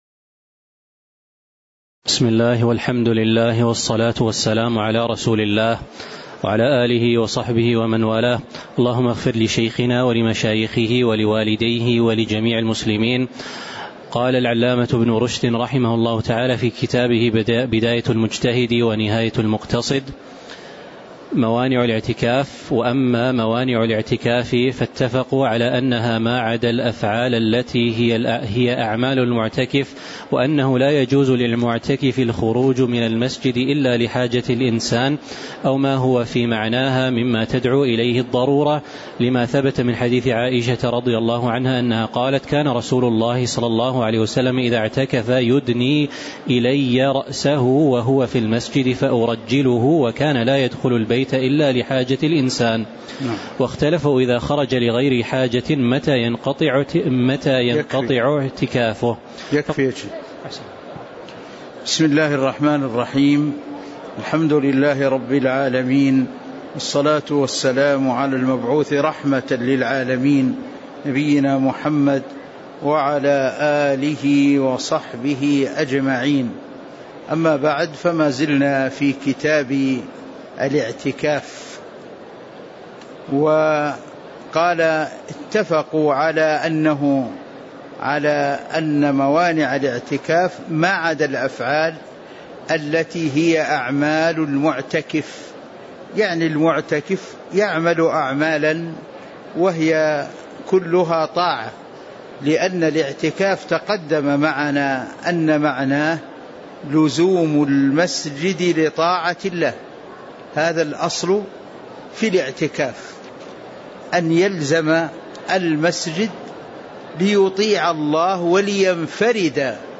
تاريخ النشر ٣ شعبان ١٤٤٦ هـ المكان: المسجد النبوي الشيخ